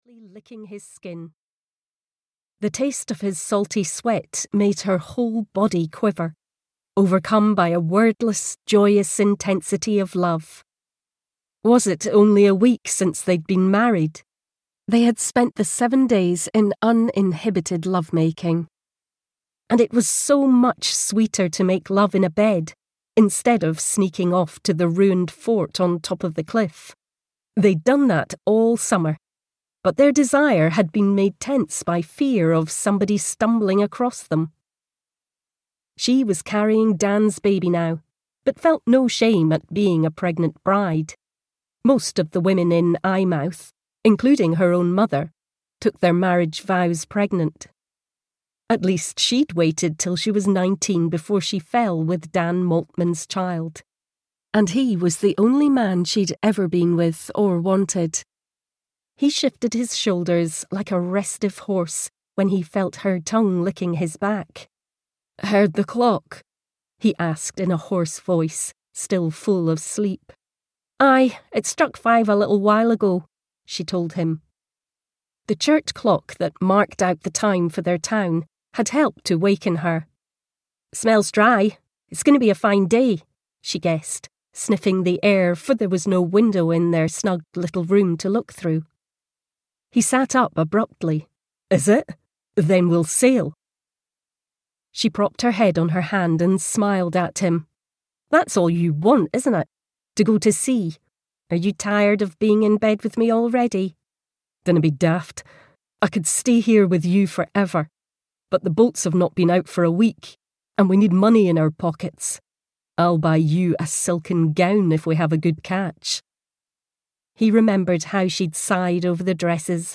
The Storm (EN) audiokniha
Ukázka z knihy